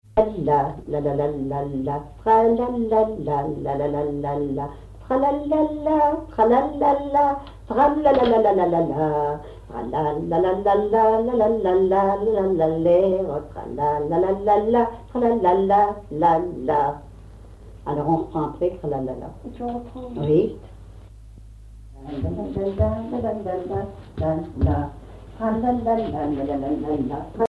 Localisation Cancale (Plus d'informations sur Wikipedia)
Fonction d'après l'analyste danse : polka des bébés ou badoise ;
Usage d'après l'analyste gestuel : danse ;
Catégorie Pièce musicale inédite